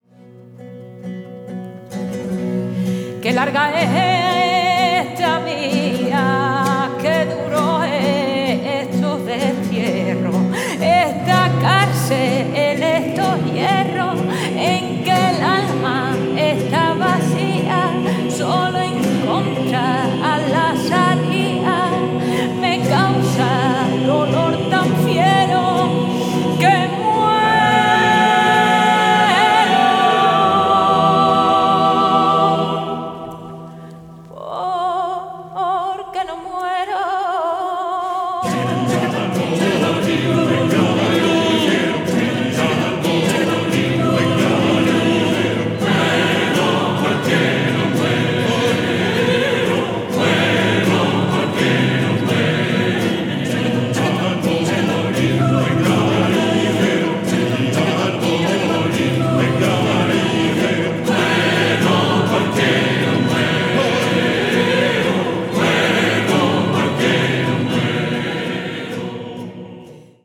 the programme juxtaposes choral singing with flamenco
have been arranged to include choral voices
24 singers
2 instrumentalists (theorbo and viol)